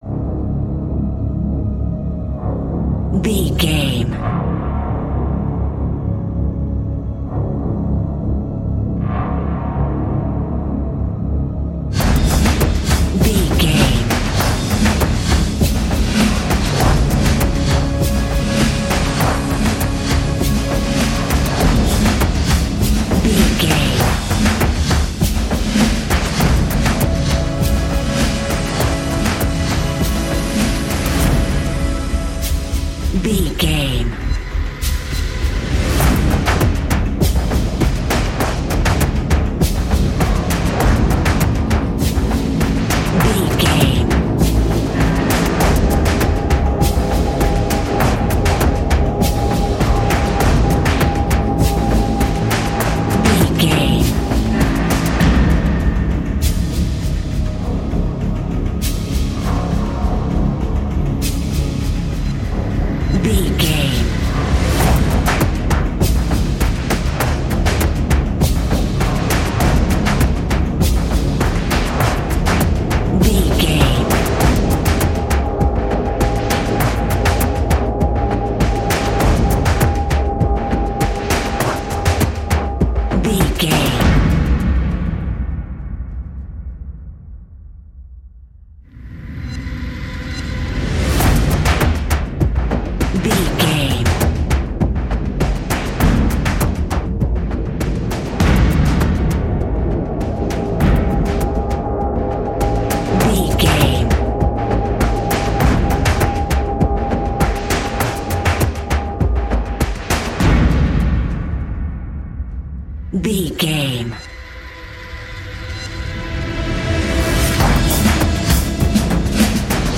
Fast paced
In-crescendo
Ionian/Major
C♯
industrial
dark ambient
EBM
drone
synths
Krautrock